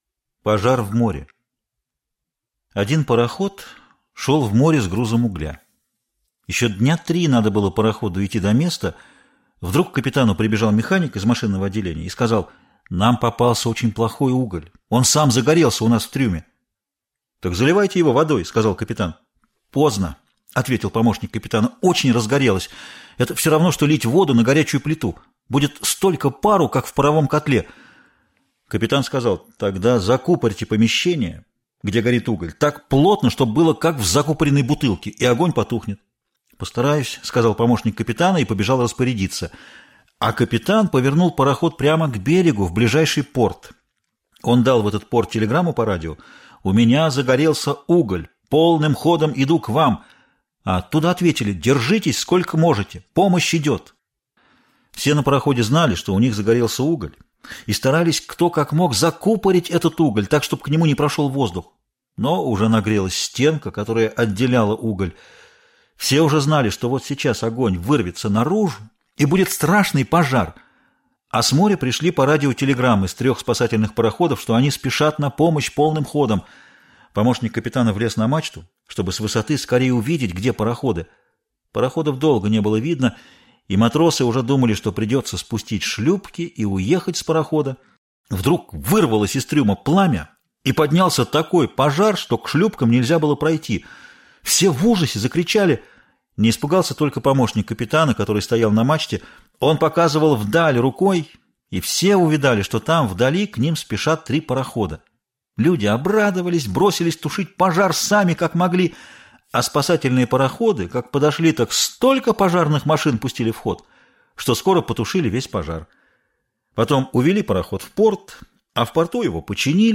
Аудиорассказ «Пожар в море»